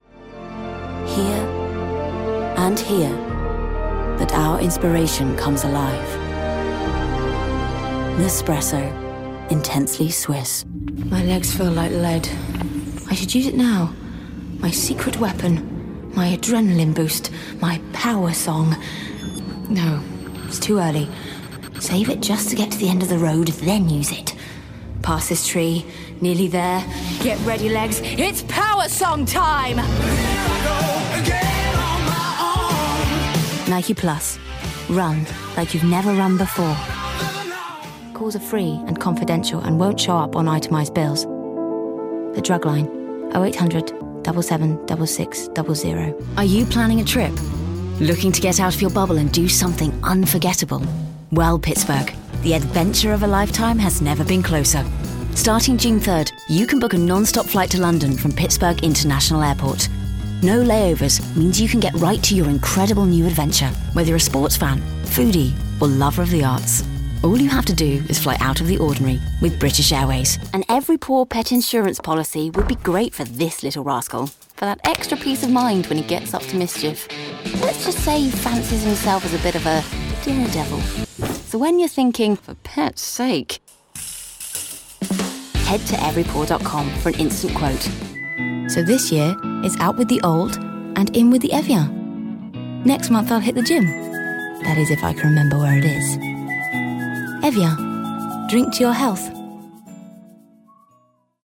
Female
Authoritative , Cheeky , Confident , Cool , Corporate , Engaging , Friendly , Natural , Posh , Reassuring , Smooth , Warm , Witty , Versatile , Young , Approachable
commercial.mp3